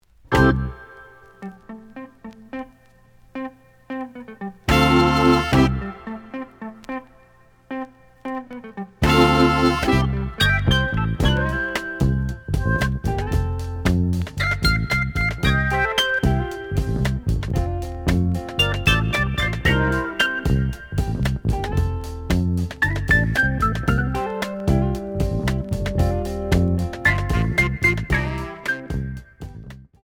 The audio sample is recorded from the actual item.
●Genre: Jazz Funk / Soul Jazz
Slight edge warp. But doesn't affect playing. Plays good.